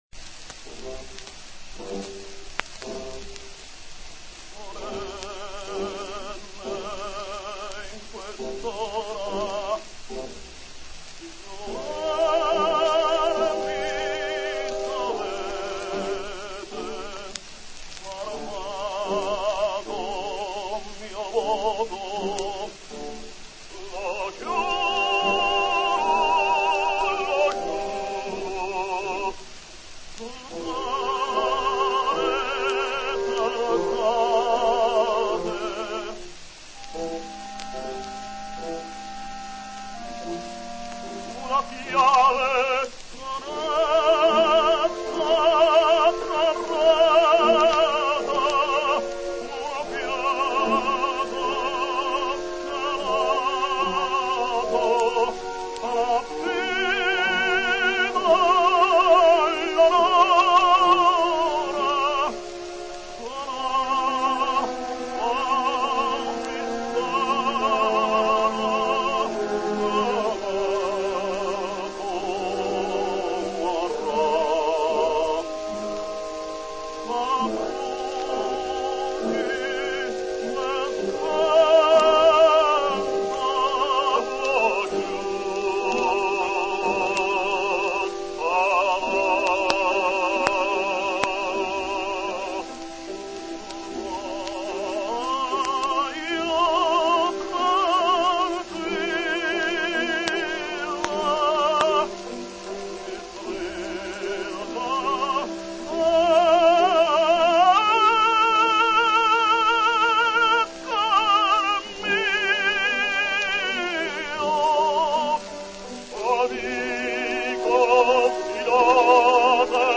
Gramophone, Milano, 17 April 1909